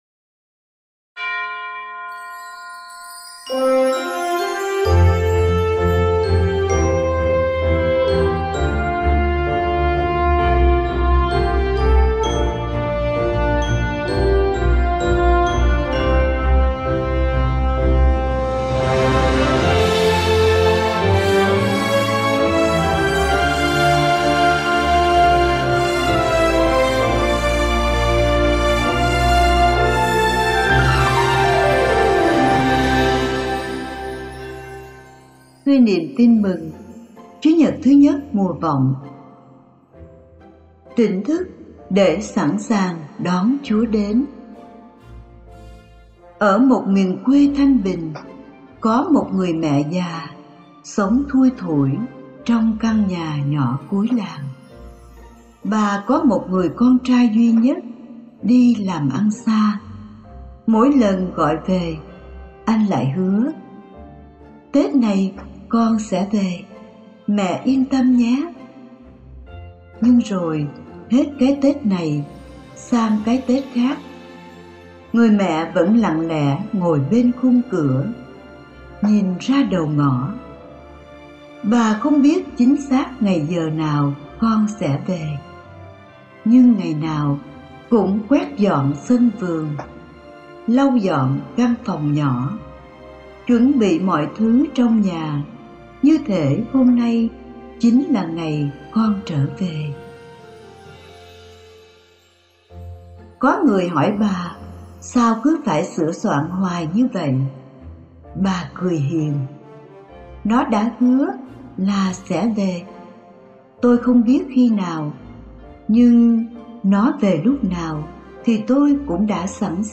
Tỉnh thức để sẵn sàng đón Chúa đến (Suy niệm Chúa nhật I mùa vọng A - 2025)